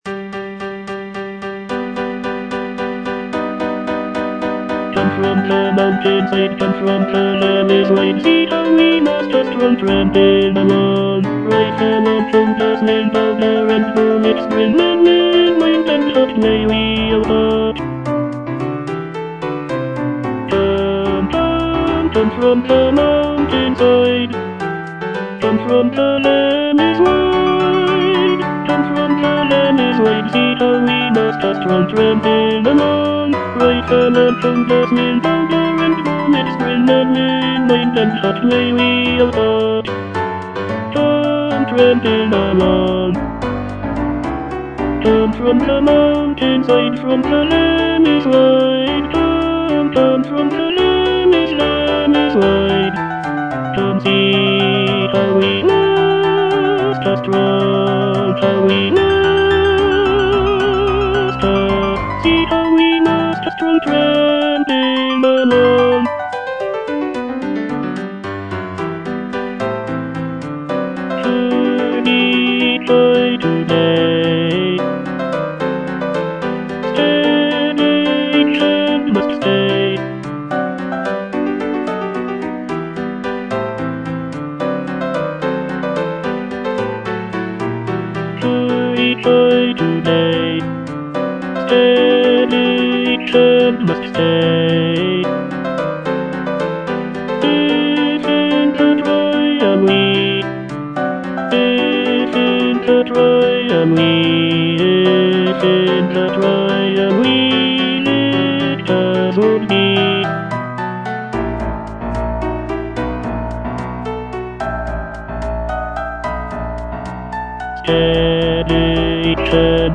E. ELGAR - FROM THE BAVARIAN HIGHLANDS The marksmen (tenor II) (Voice with metronome) Ads stop: auto-stop